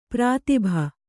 ♪ prātibha